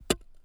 suppression de la reverb sur les sfx de pioche
pickaxe_1.wav